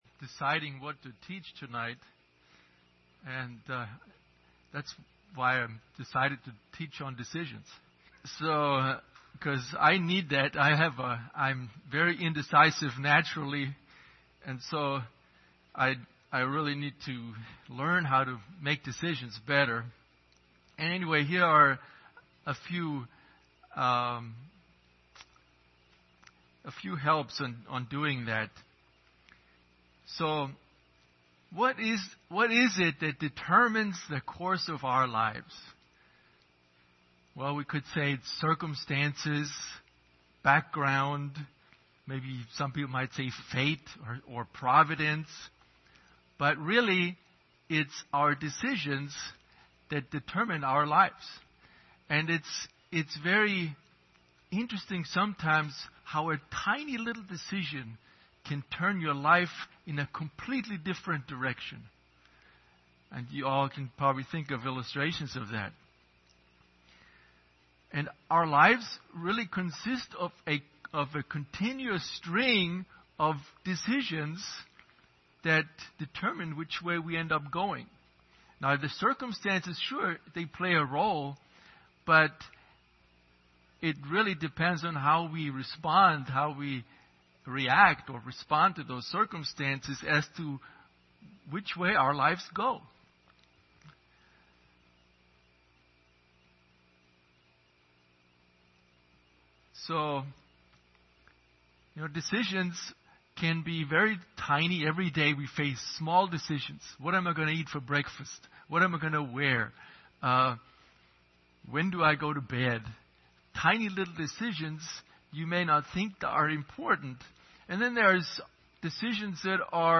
Passage: 2 Timothy 3:16-17 Service Type: Wednesday Evening